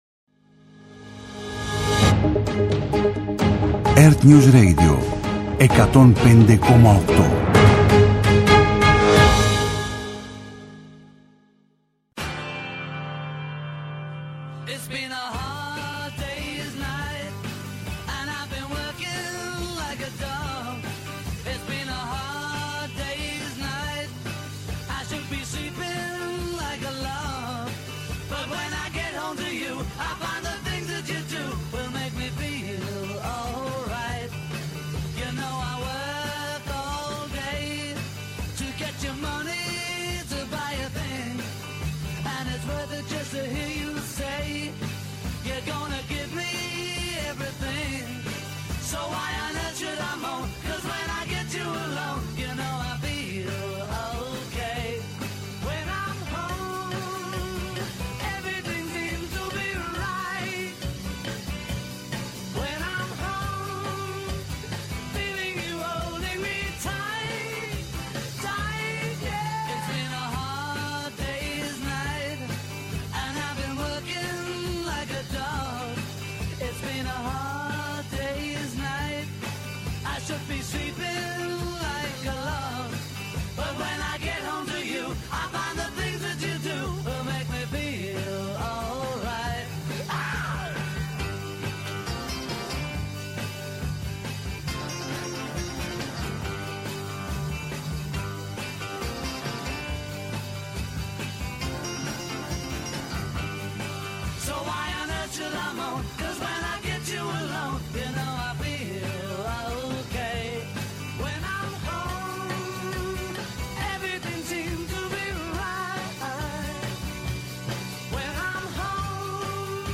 Με αναλύσεις, πρακτικές συμβουλές και συνεντεύξεις με πρωτοπόρους στην τεχνολογία και τη δημιουργικότητα, τα «Ψηφιακά Σάββατα» σας προετοιμάζει για το επόμενο update.